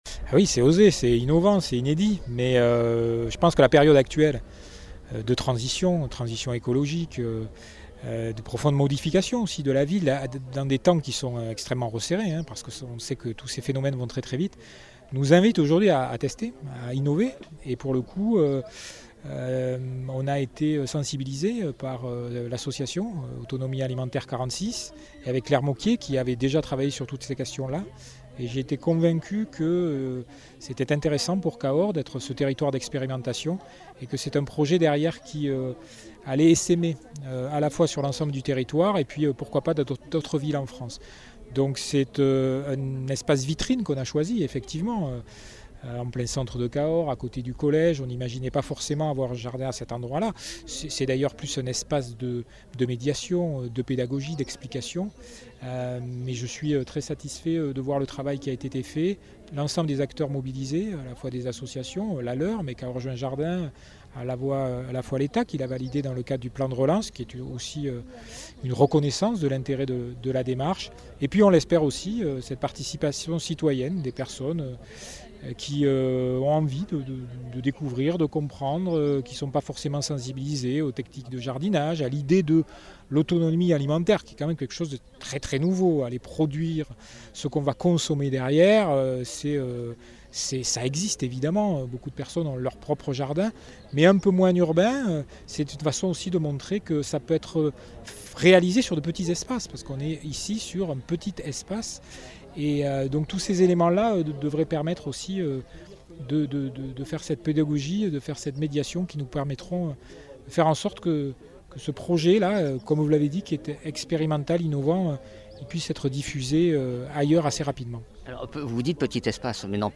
Interviews
Invité(s) : Jean Marc Vayssouze-Faure, Maire de Cahors